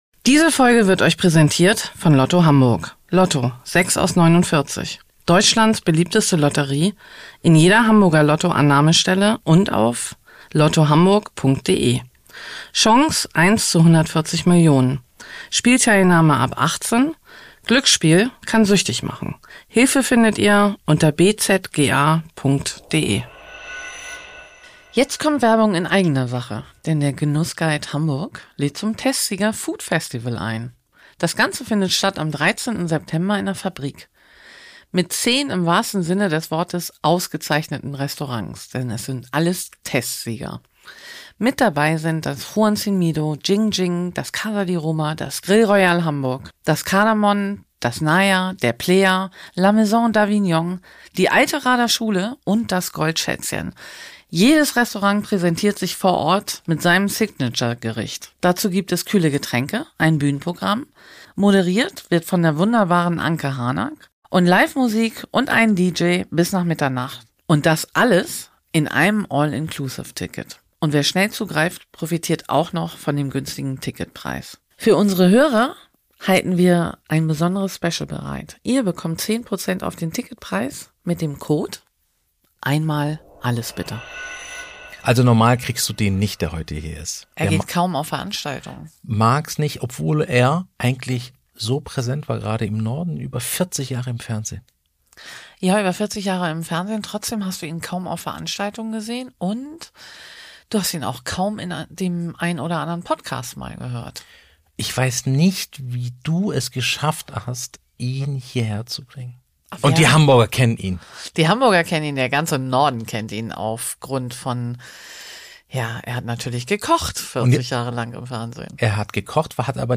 In dieser Folge von „Einmal ALLES, bitte!“ wird geschnackt, gelacht und zurückgeblickt: Rainer Sass